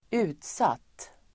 Uttal: [²'u:tsat:]